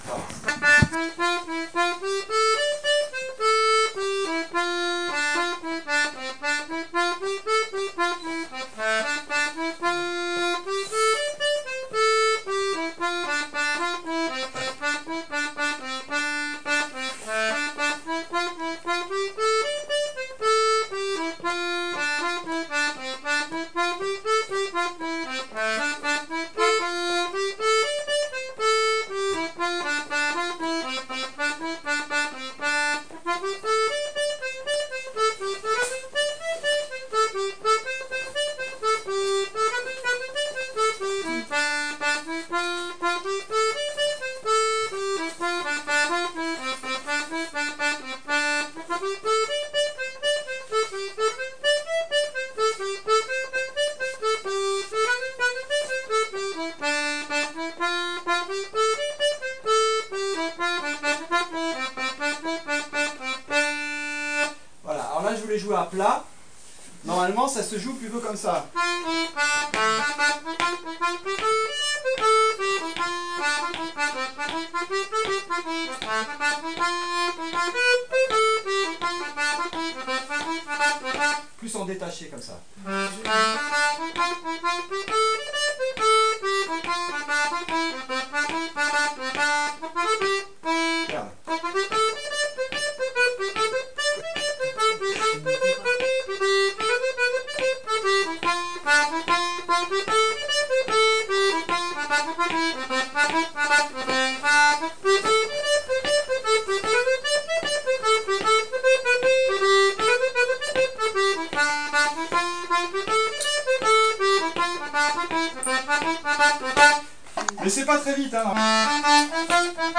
l'atelier d'accordéon diatonique